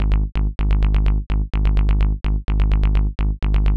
• Bass sequence.wav
Bass_001_Am__knx.wav